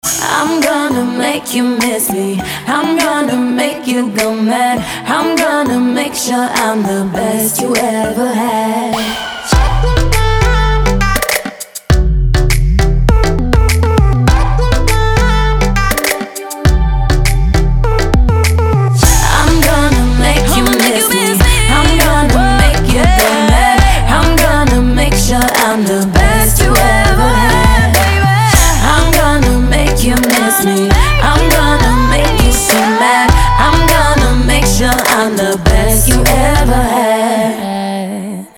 красивые
женский вокал
dance
RnB